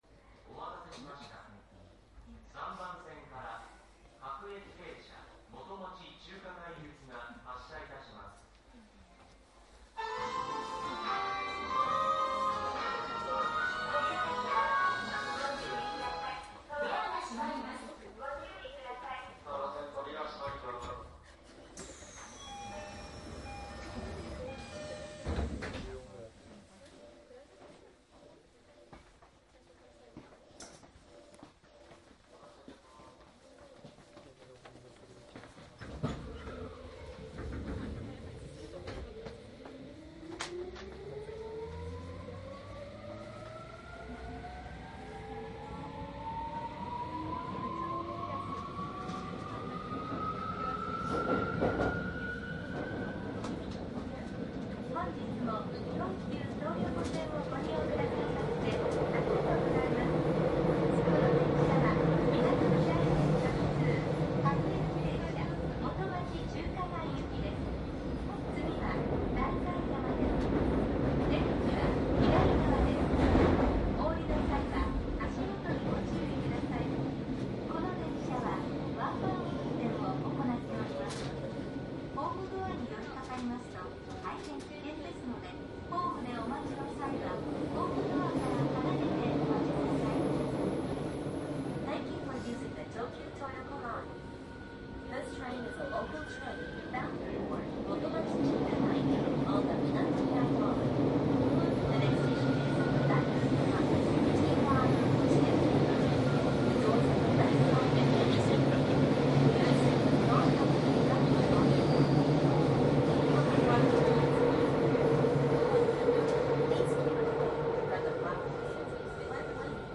♫東京メトロ１７０００系（8両編成）　東横線　　方面　走行音　 CD♪
副都心線の一部区間と東横線内の録音。
■【各停】新宿三丁目→菊名     ＜DATE23-１２-３＞
マスター音源はデジタル44.1kHz16ビット（マイクＥＣＭ959）で、これを編集ソフトでＣＤに焼いたものです。